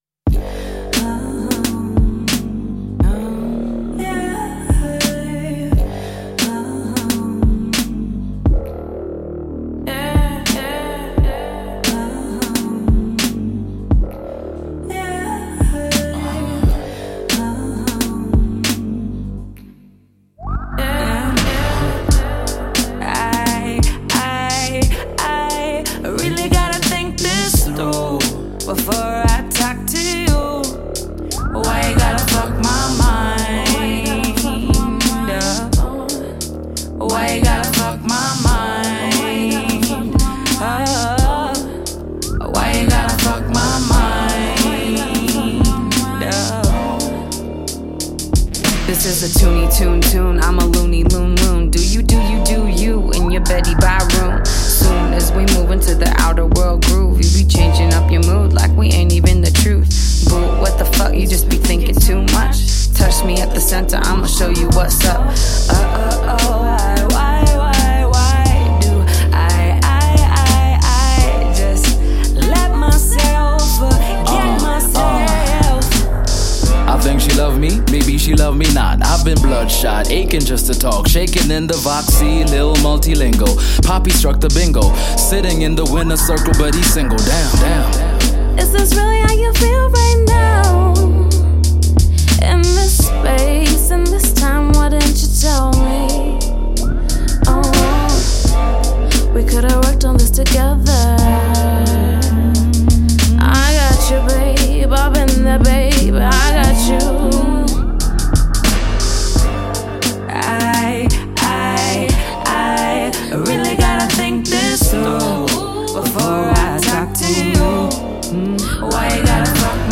Its that dope Electro-Organic music.
be it Funk, Hip Hop, Soul, Jazz, Tribal and beyond.